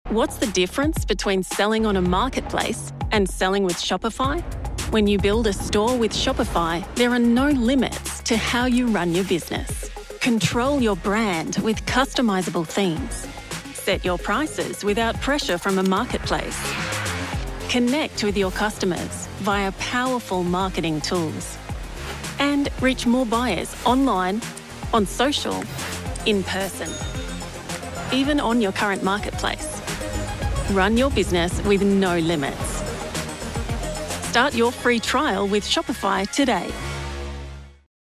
Female
Television Spots
Shopify - Authentic Commercial
Words that describe my voice are Medical narration expert, Warm and comfortable, Authentic Australian.